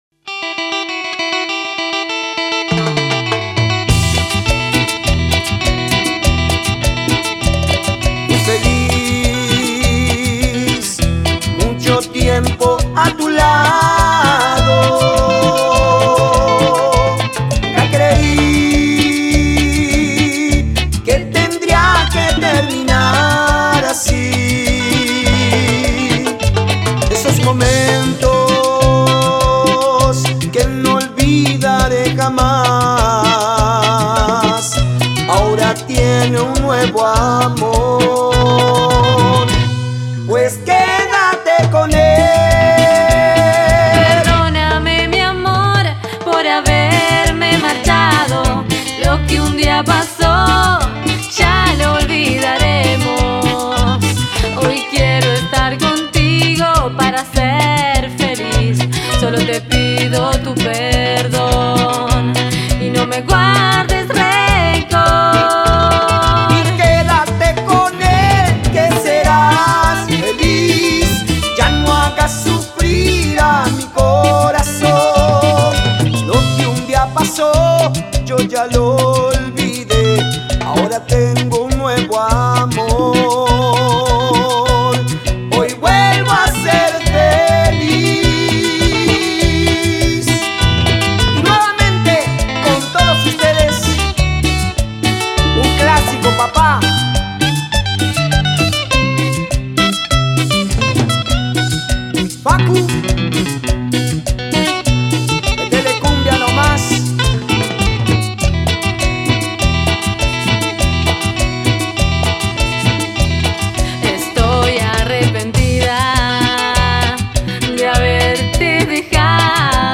Guitarra Base
Guiro
Octapad